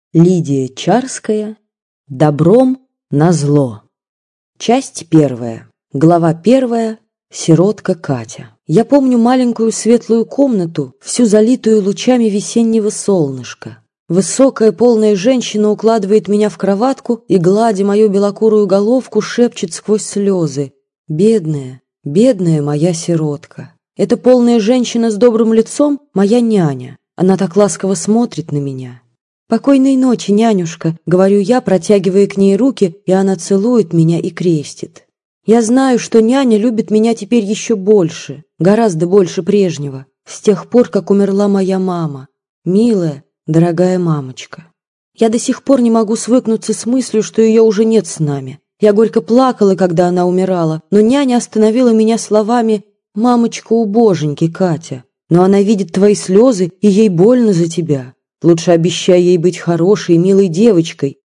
Аудиокнига Добром на зло | Библиотека аудиокниг